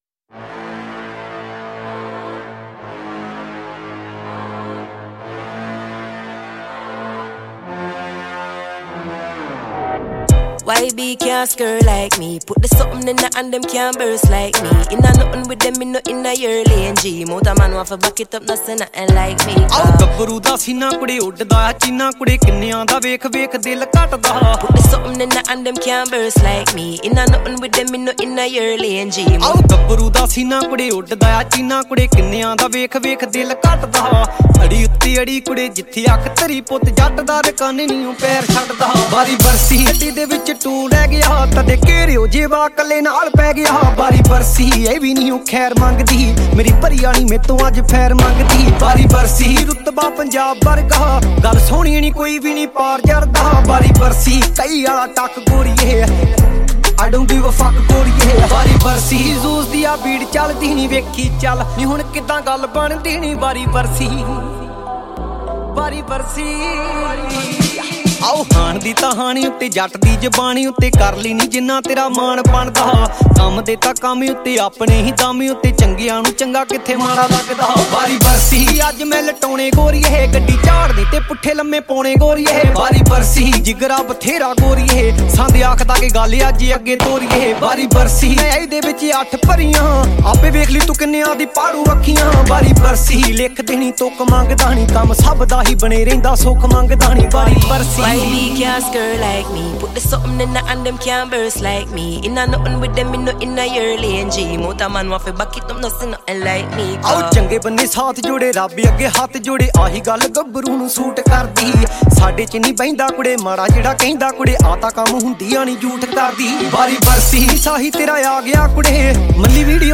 old Punjabi song